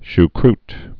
(sh-krt)